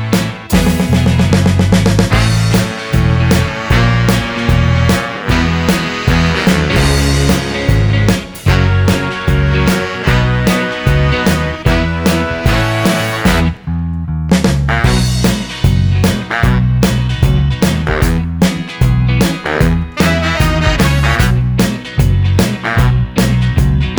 No Backing Vocals Rock 'n' Roll 3:12 Buy £1.50